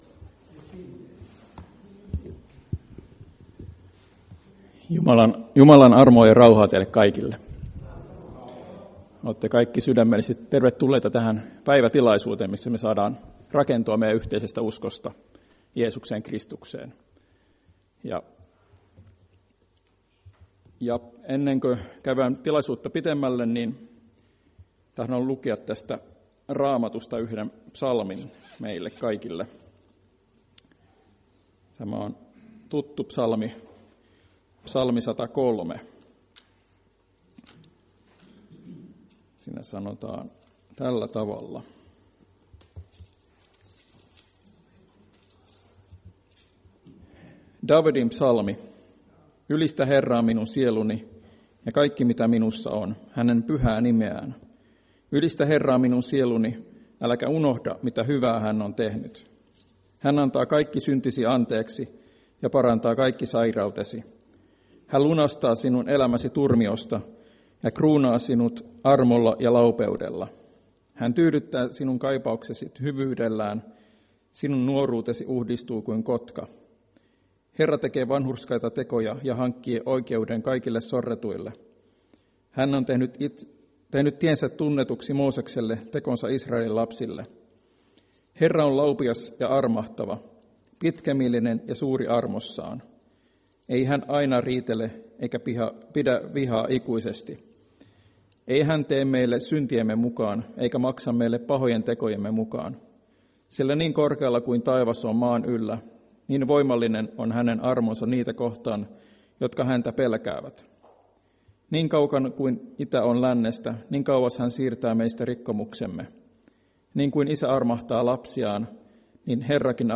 Päiväkokous 9.2.2025